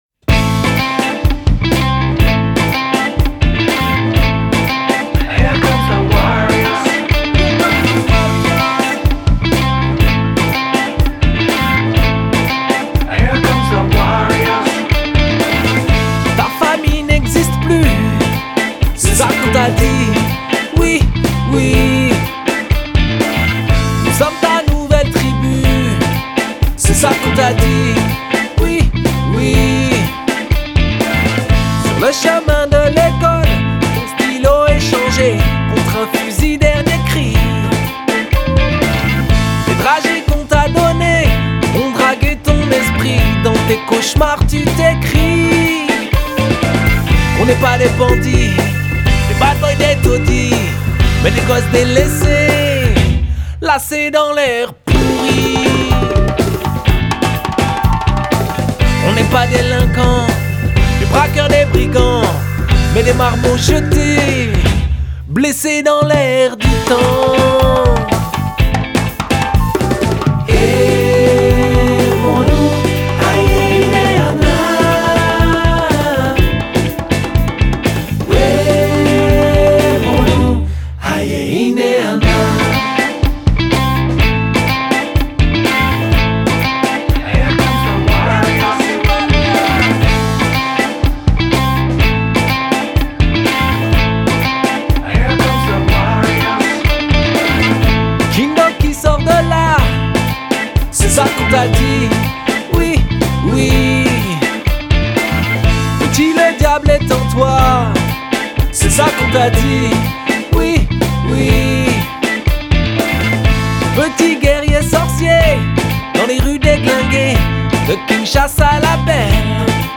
Genre: World, Folk